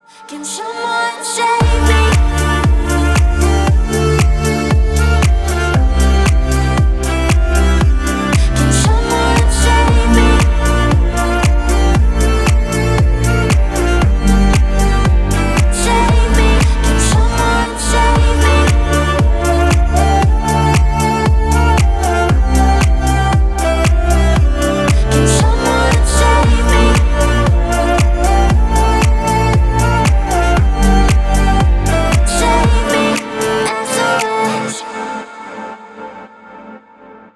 • Качество: 320, Stereo
deep house
восточные мотивы
красивая мелодия
скрипка
расслабляющие